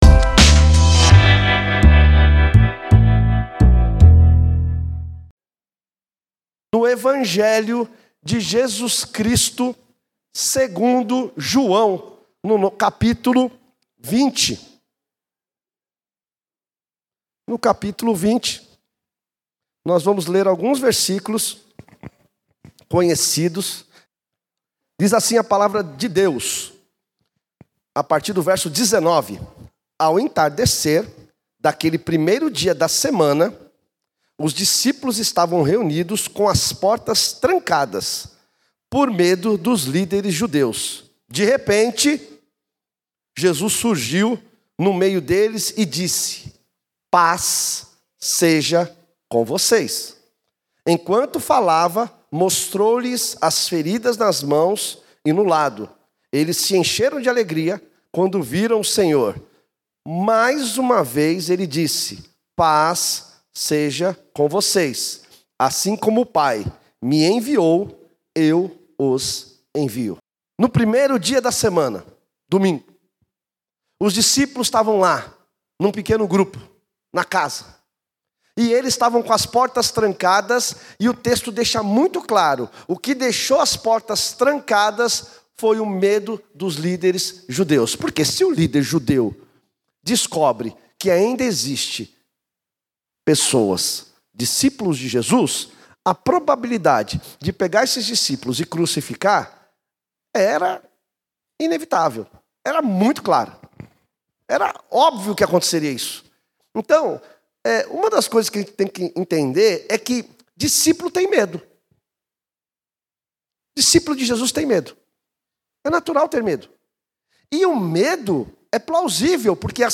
Conferência Missionária de 2022.